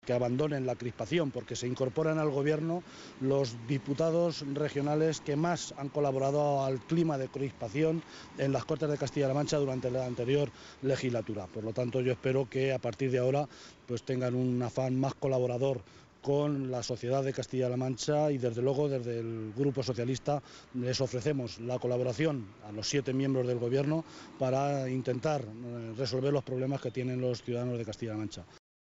José Luis Martínez Guijarro, portavoz del Grupo Parlamentario Socialista.
Cortes de audio de la rueda de prensa